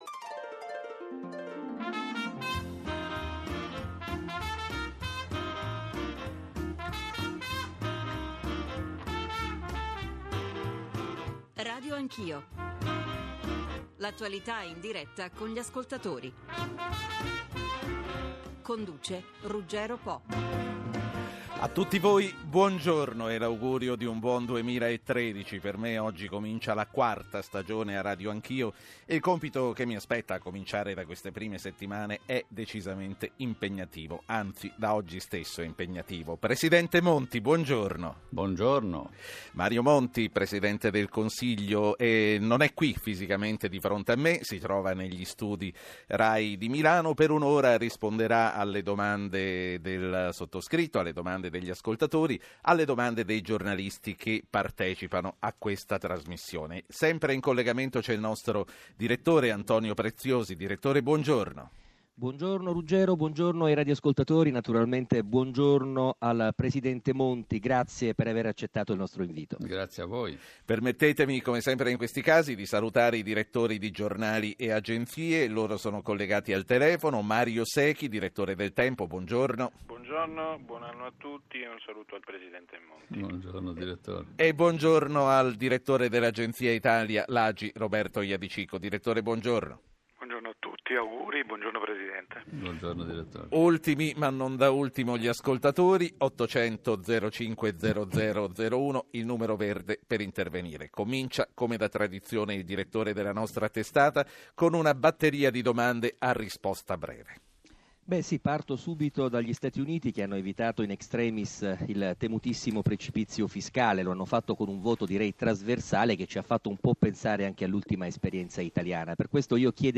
Entonces os aconsejo formar vuestra opinión escuchando la rueda de prensa de Monti del 23/12/2012 y la transmisión de Lucia Annunciata ¨1/2 ora», que permite de cierto entender el programa «Agenda Monti», la personalidad y la credibilidad de su promotor.